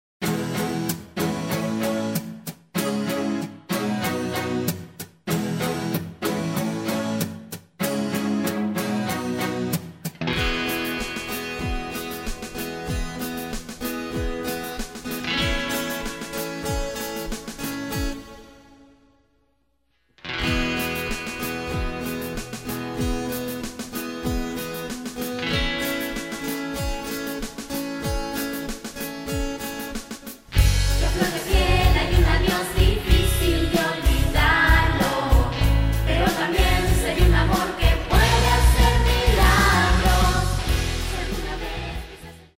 Algunas bases musicales tienen incorporado los coros